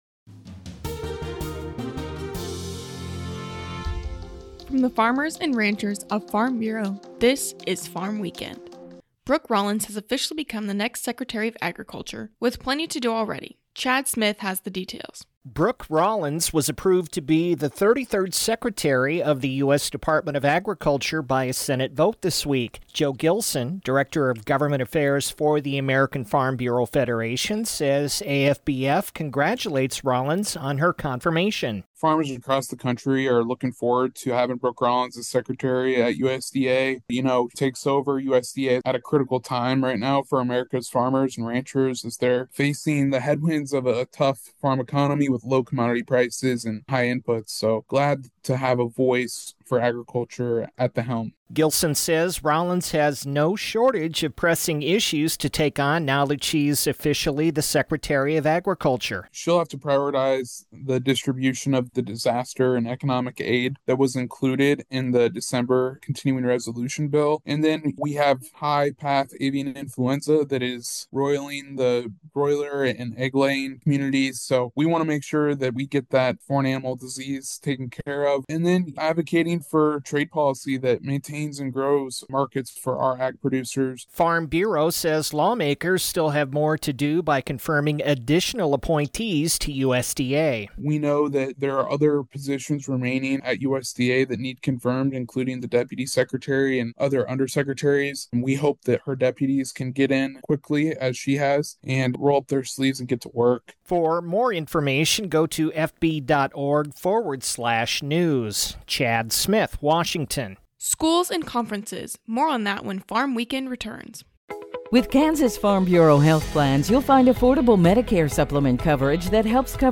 A 5-minute radio program featuring agriculture news and commentary from the last week.